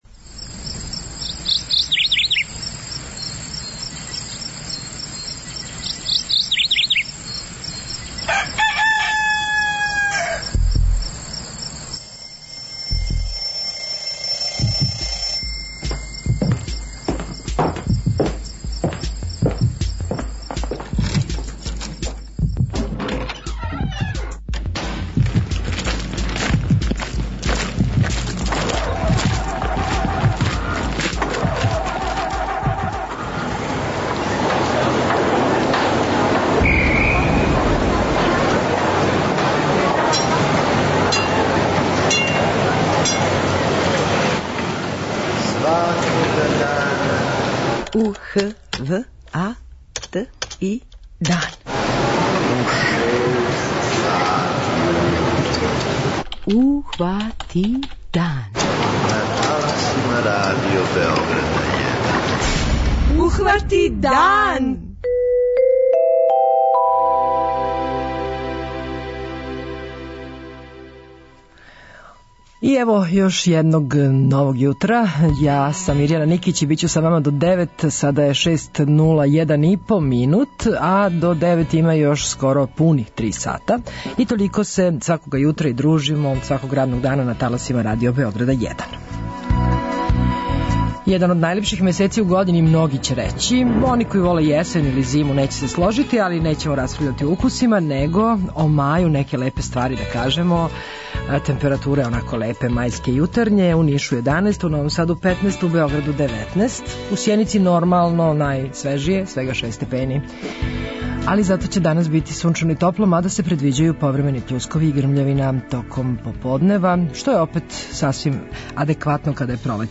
У Јутарњем програму говоримо, између осталог: